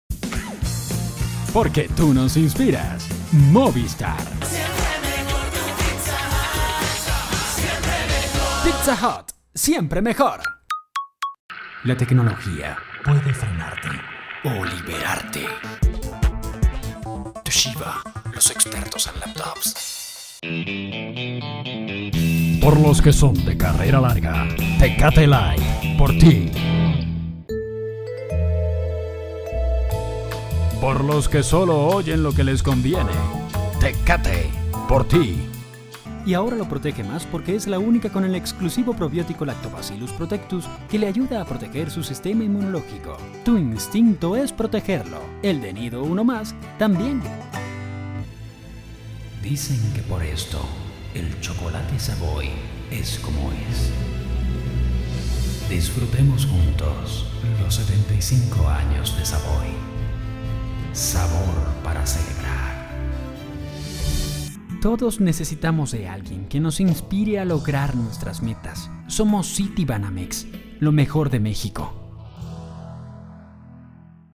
Professional Neutral Spanish Voiceover. (Dubbing, Audiobooks, Commercials or any proyect).
Sprechprobe: Werbung (Muttersprache):